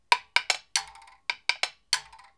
• wooden temple blocks studio sample.wav
Recorded in a professional studio with a Tascam DR 40 linear PCM recorder.
wooden_temple_blocks_studio_sample_LkU.wav